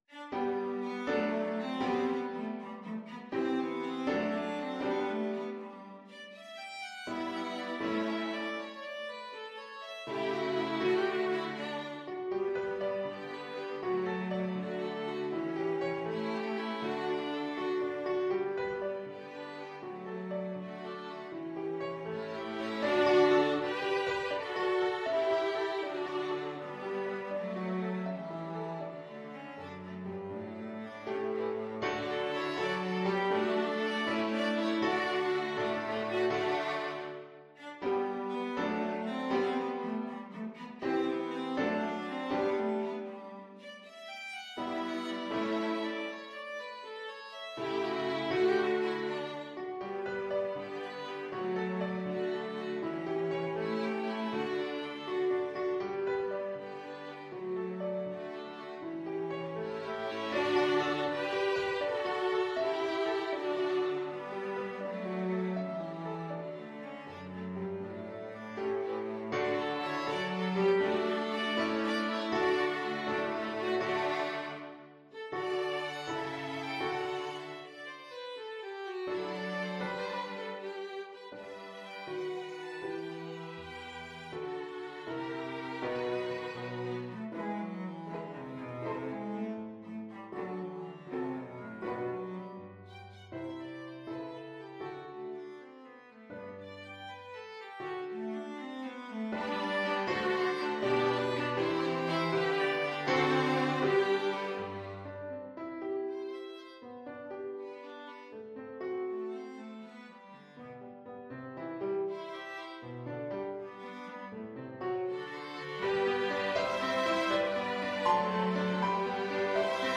Violin 1Violin 2ViolaCelloPiano
G major (Sounding Pitch) (View more G major Music for Piano Quintet )
6/8 (View more 6/8 Music)
Allegro con brio (View more music marked Allegro)
Piano Quintet  (View more Intermediate Piano Quintet Music)
Classical (View more Classical Piano Quintet Music)